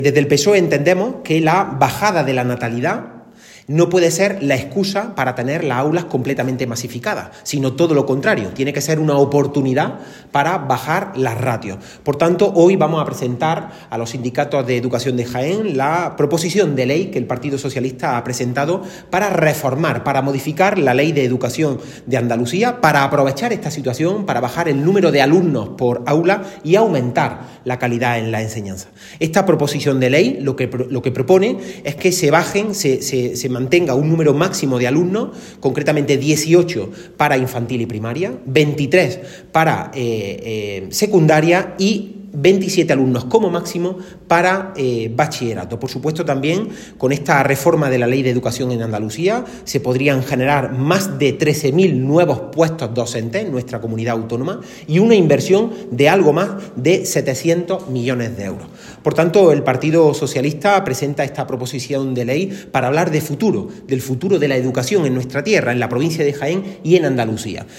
El secretario general del PSOE de Jaén hizo estas declaraciones antes de reunirse con los sindicatos educativos de CSIF, ANPE, USTEA, CCOO y UGT, a los que presentó el contenido de la Proposición de Ley que ha registrado el PSOE en el Parlamento andaluz.